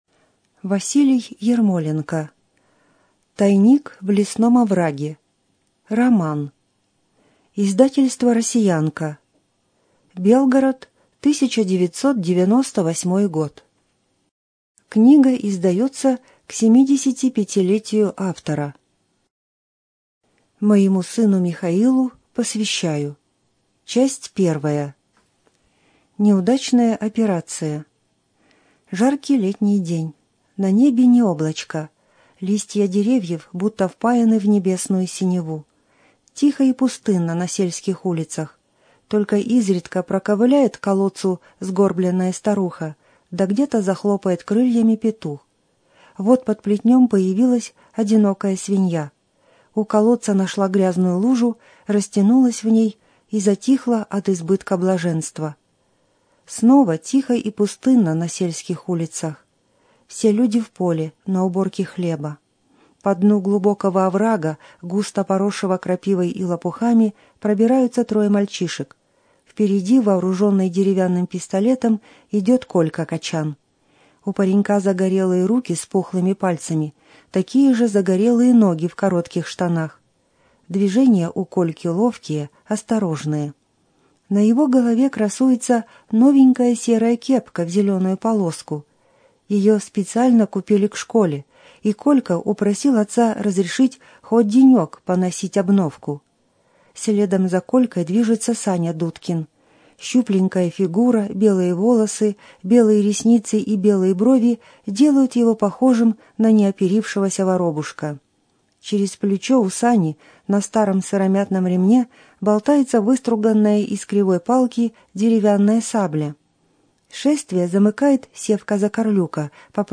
Студия звукозаписиБелгородская областная библиотека для слепых имени Василия Яковлевича Ерошенко